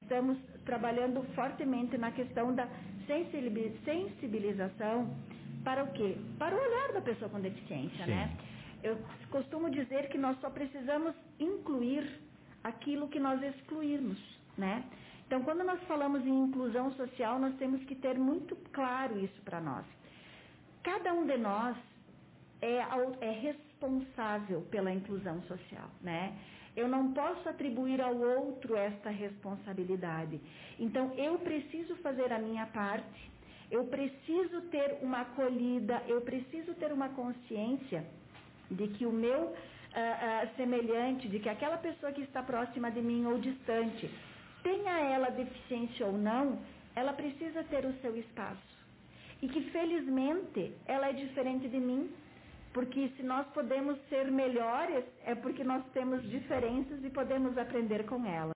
Confira a transmissão ao vivo e a entrevista completa, realizada na página do Facebook da Rádio Comunitária.